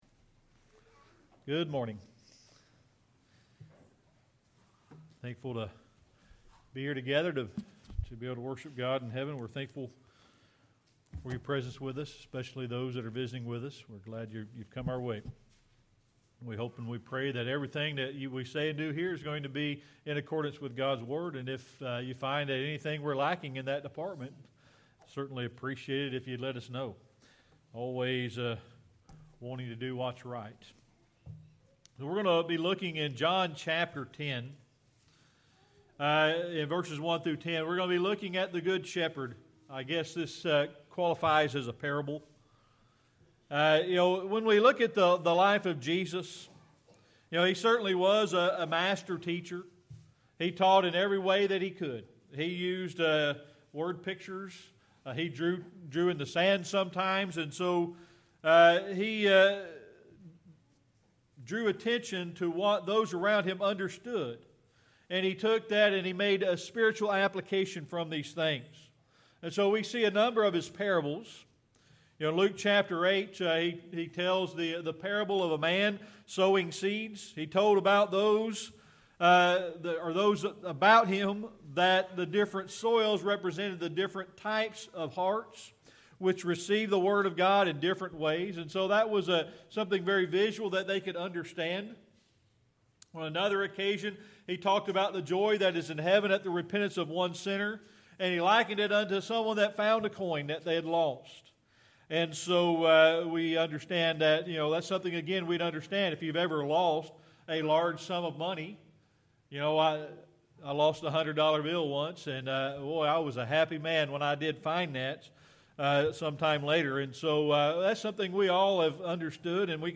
John 10:1-10 Service Type: Sunday Morning Worship We're going to be looking at the Good Shepherd.